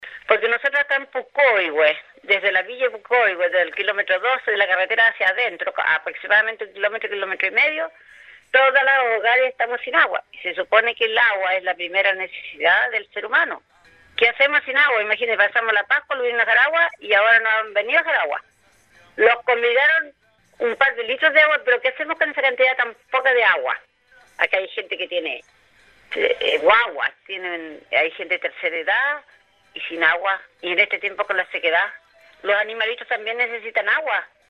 Así lo reclamó una representante de las familias del sector, quien señaló que la irregularidad del suministro se ha transformado en un problema constante